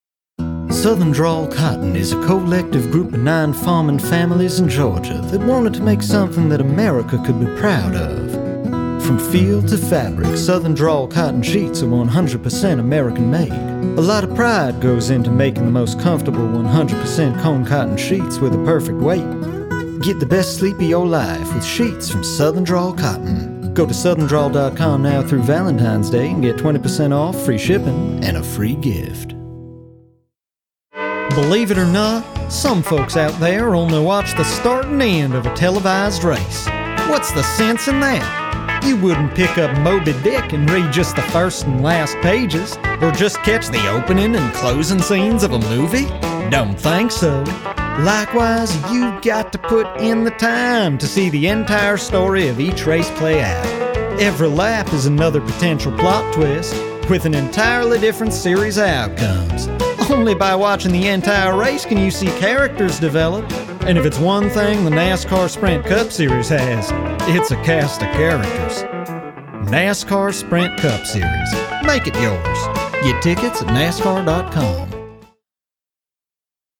American Southern Accent V/O Reel
Voice Over
Voiceover